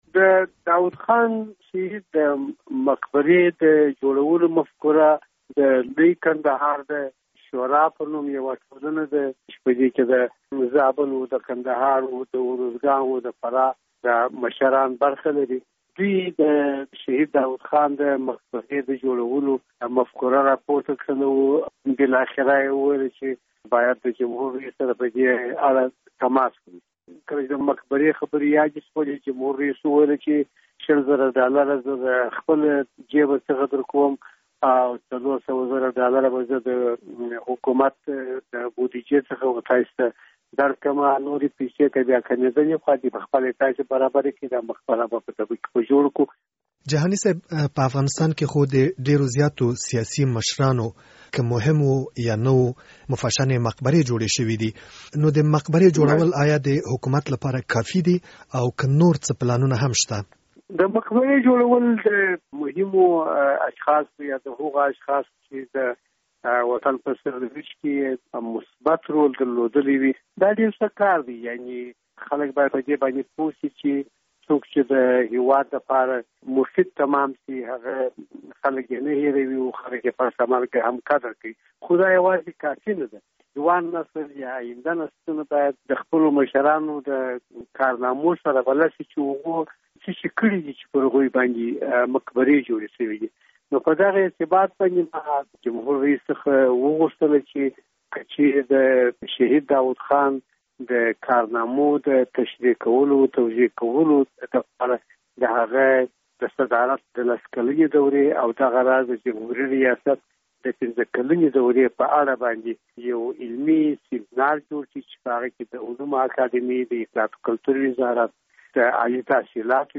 مرکه
د شهید سردار محمد داود خان د مقبرې د جوړولو په اړه داطلاعاتو او فرهنګ له وزیر عبدالباري جهاني سره مرکه